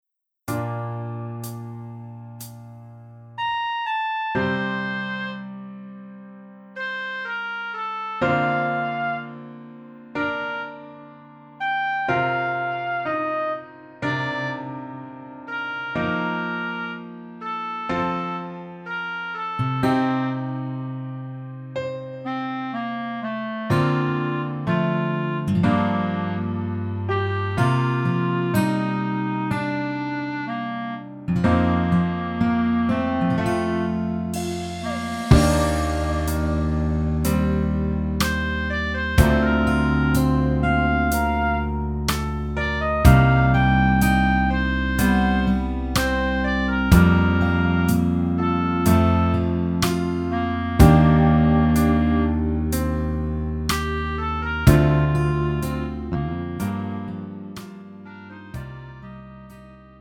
음정 -1키 4:06
장르 가요 구분 Pro MR
Pro MR은 공연, 축가, 전문 커버 등에 적합한 고음질 반주입니다.